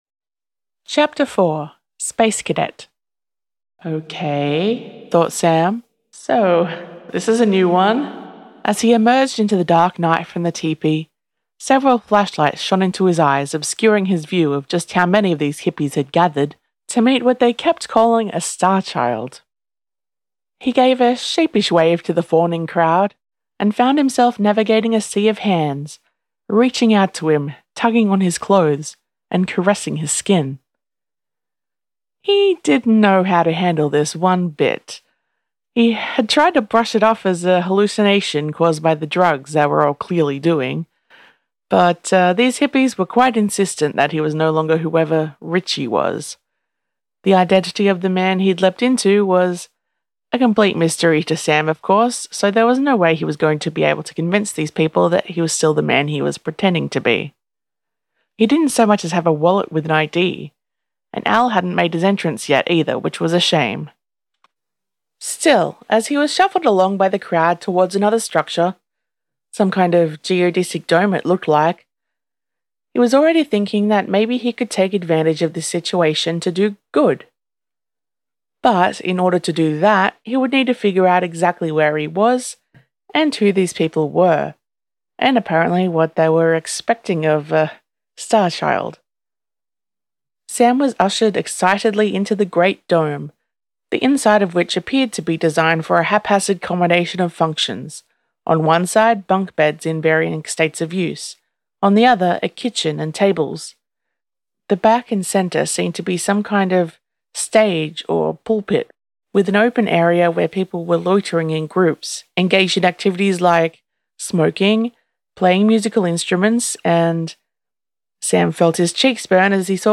Listen to/download this chapter narrated by the author: